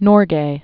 (nôrgā), Tenzing 1914-1986.